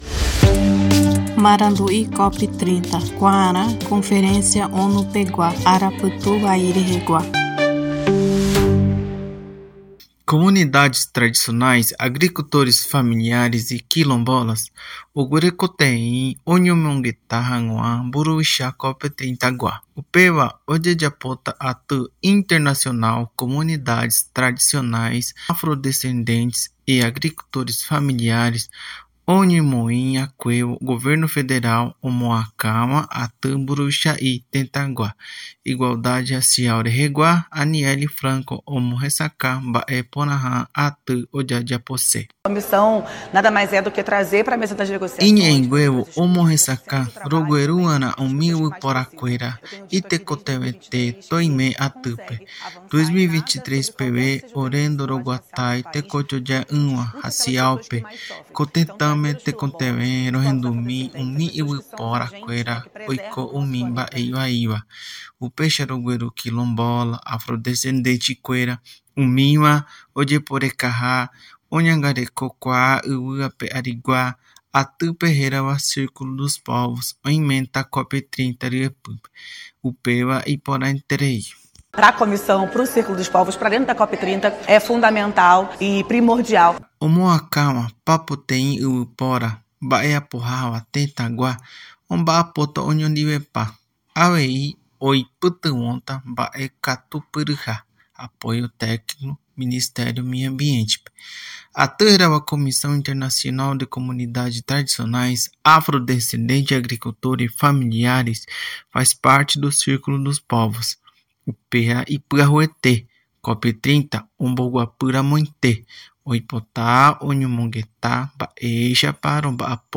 Liderado pela ministra da Igualdade Racial, Anielle Franco, o grupo faz parte do Círculo dos Povos, iniciativa da presidência da Conferência para garantir espaço da sociedade civil. Ouça a reportagem e saiba mais.